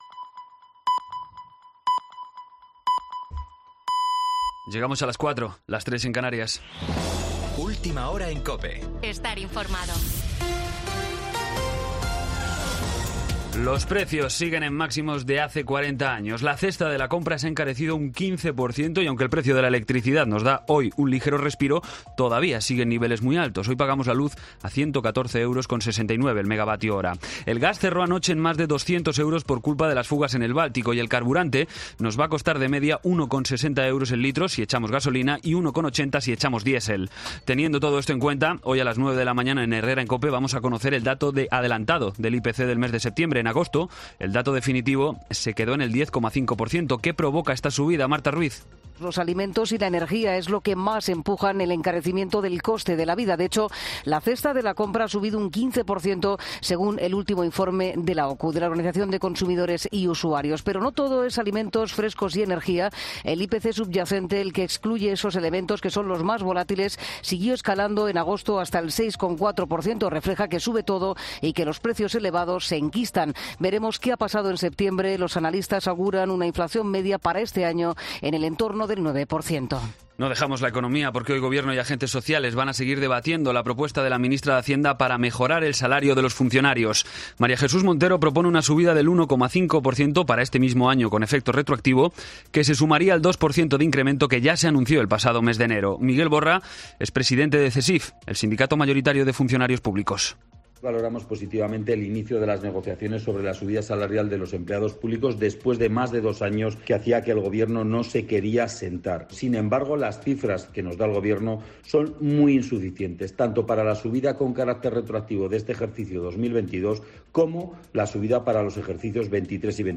Boletín de noticias COPE del 29 de septiembre a las 04:00 hora
AUDIO: Actualización de noticias Herrera en COPE